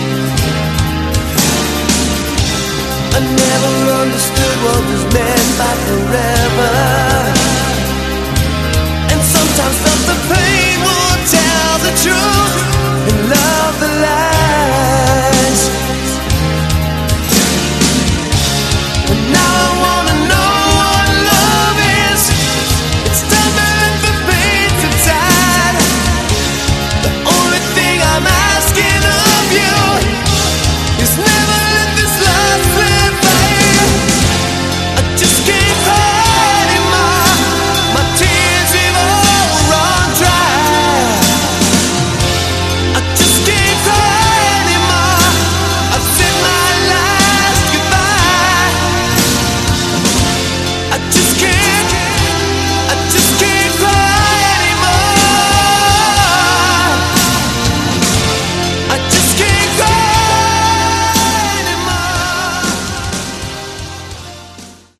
Category: Hard Rock
lead vocals
guitar, keyboards
bass
drums